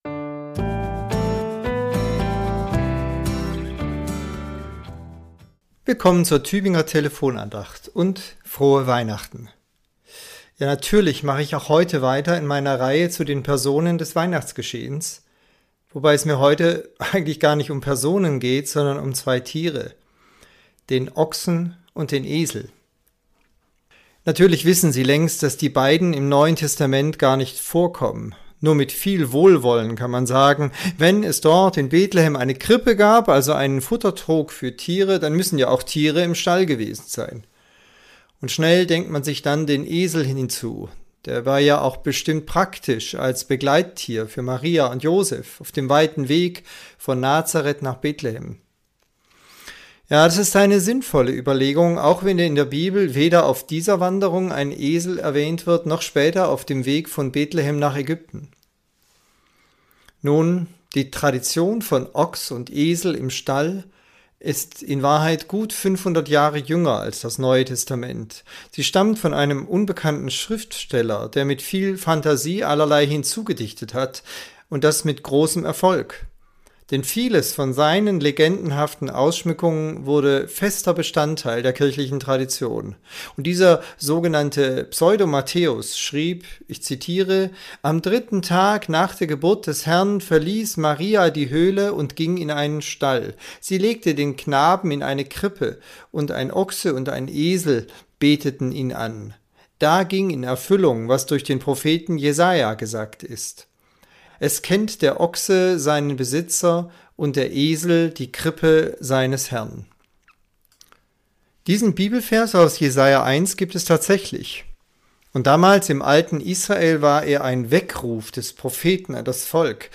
Andacht zur Weihnachtswoche Teil 6